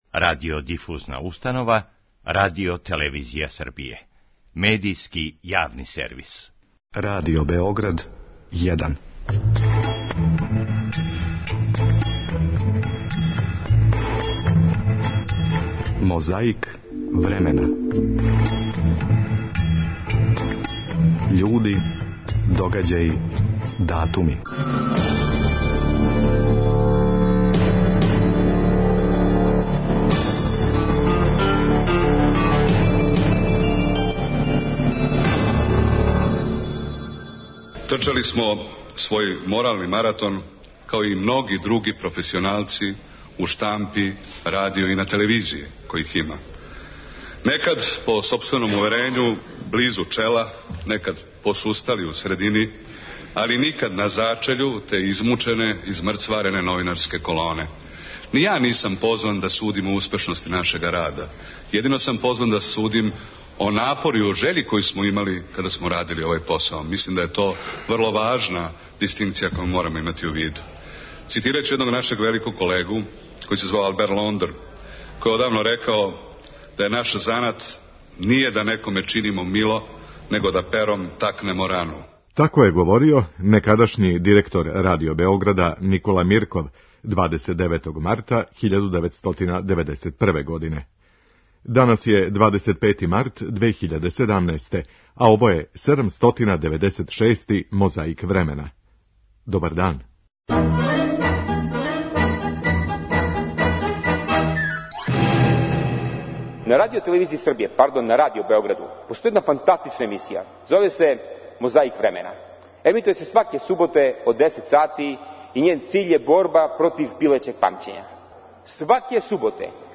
Тако је то када 1978. године чаврљају два новинара, глумац, сликар и писац.
Подсећа на прошлост (културну, историјску, политичку, спортску и сваку другу) уз помоћ материјала из Тонског архива, Документације и библиотеке Радио Београда.